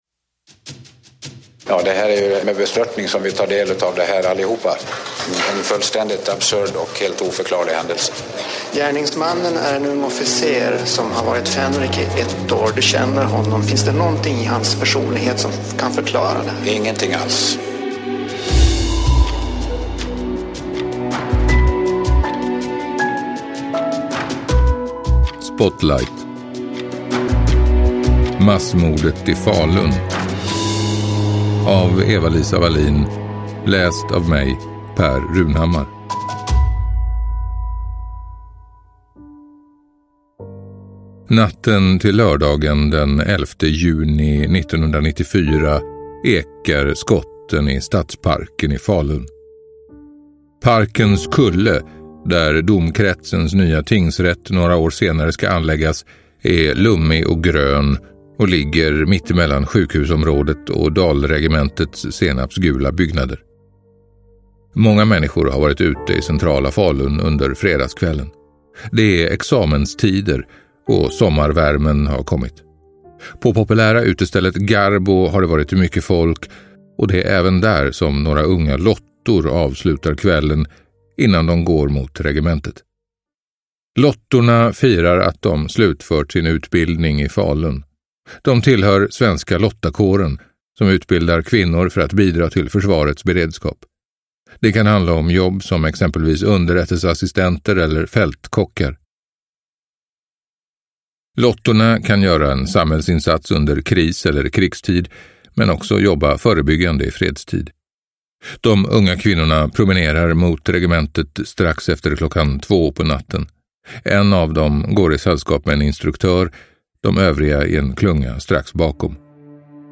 Massmordet i Falun – Ljudbok – Laddas ner